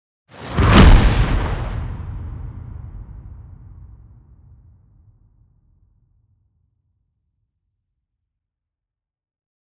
دانلود آهنگ رعدو برق 4 از افکت صوتی طبیعت و محیط
دانلود صدای رعدو برق 4 از ساعد نیوز با لینک مستقیم و کیفیت بالا
جلوه های صوتی